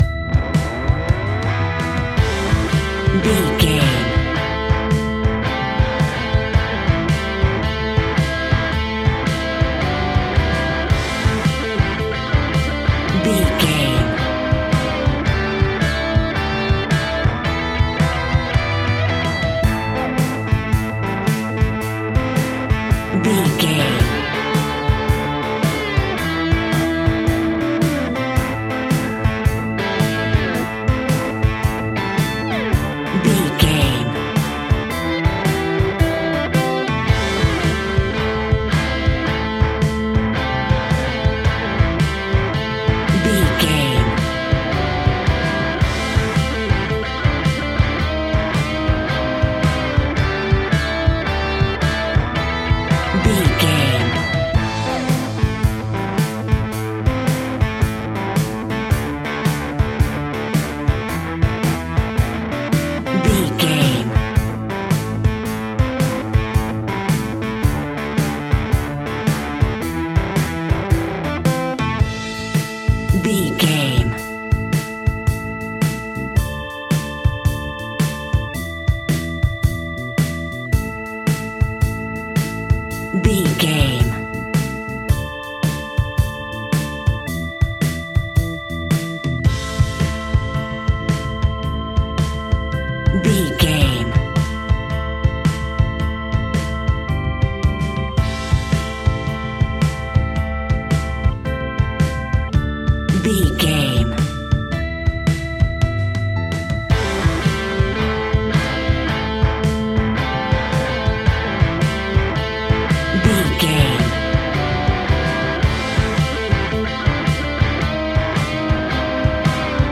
Ionian/Major
hard rock
blues rock
distortion